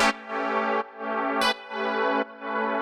GnS_Pad-MiscB1:2_170-A.wav